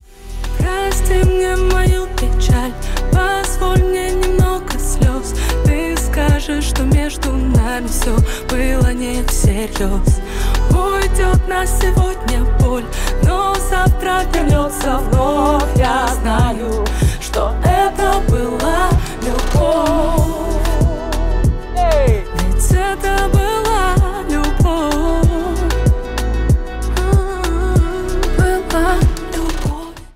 Поп Музыка
грустные
кавер